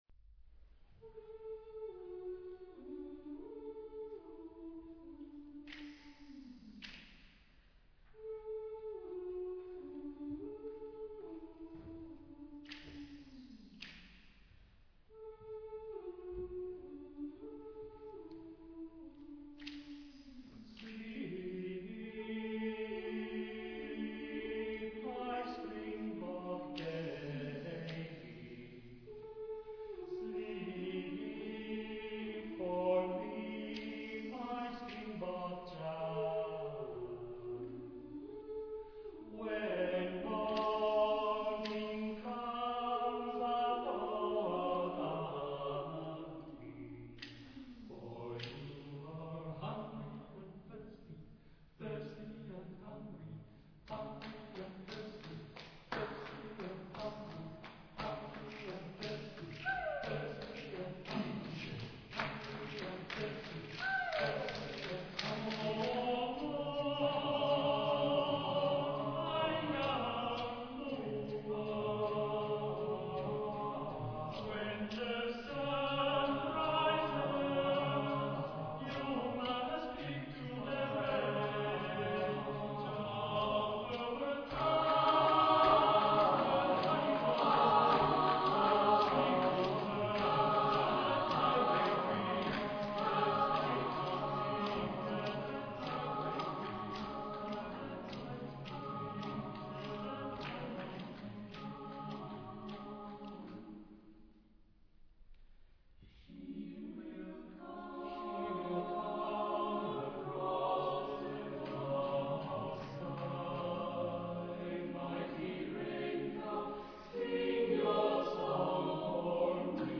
Genre-Stil-Form: weltlich
Charakter des Stückes: rhythmisch ; besinnlich ; ehrlich ; langsam
Chorgattung: SATTBB  (6 gemischter Chor Stimmen )
Tonart(en): G-Dur
Aufnahme Bestellnummer: Internationaler Kammerchor Wettbewerb Marktoberdorf